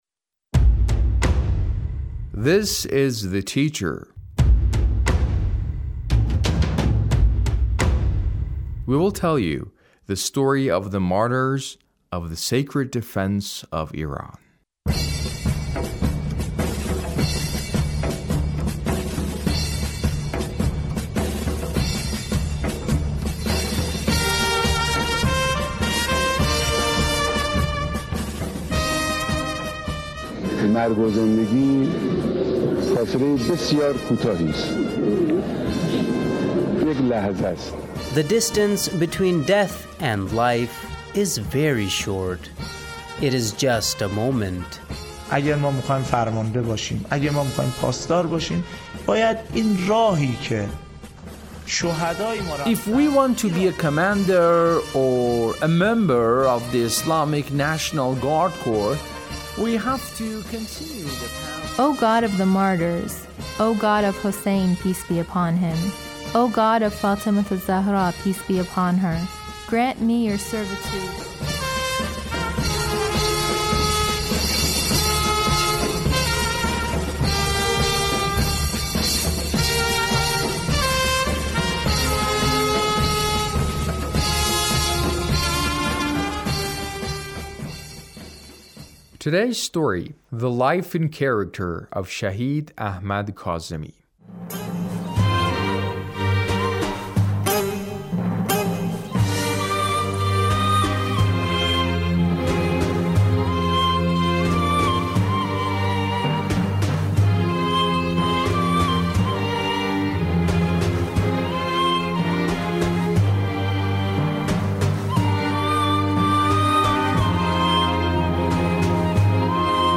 A radio documentary on the life of Shahid Ahmad Kazemi- Part 2